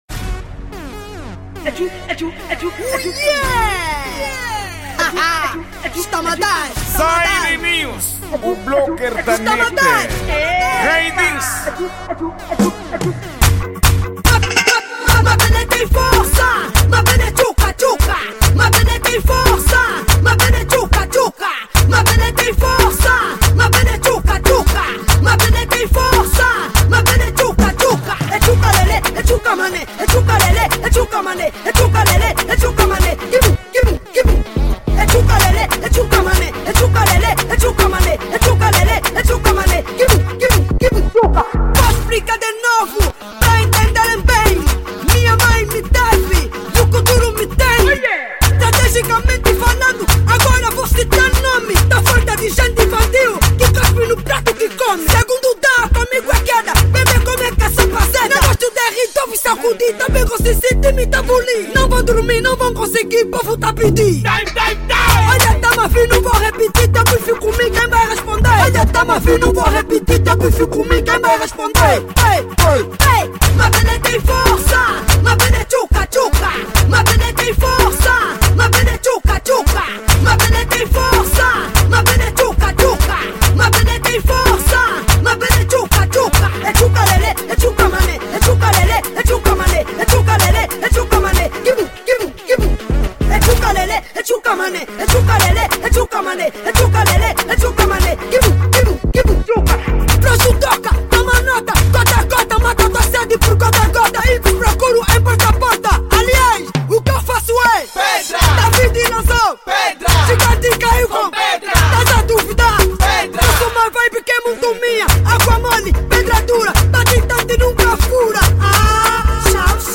Gênero:Kuduro